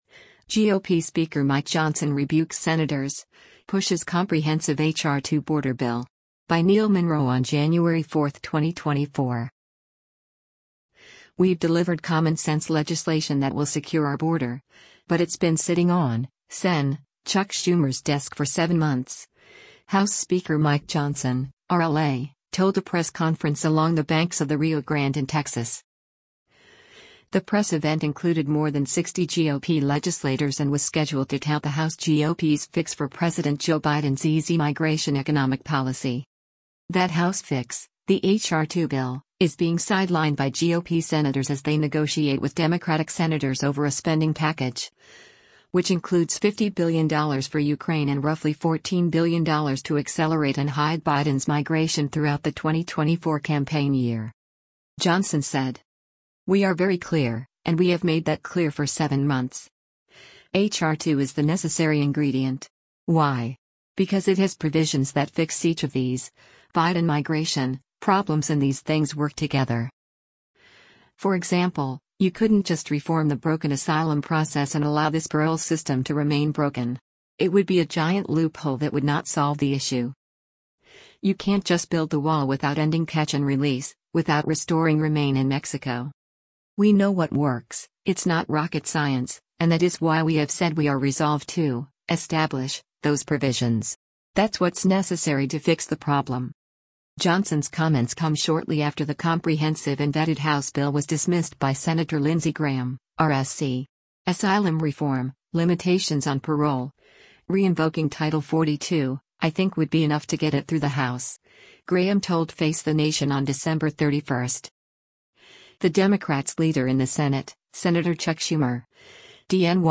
“We’ve delivered common sense legislation that will secure our border, but it’s been sitting on [Sen.] Chuck Schumer’s desk for seven months,” House Speaker Mike Johnson (R-LA), told a press conference along the banks of the Rio Grande in Texas.
The press event included more than 60 GOP legislators and was scheduled to tout the House GOP’s fix for President Joe Biden’s easy-migration economic policy.